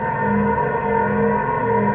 Magic_CrystalEnergyLoop02.wav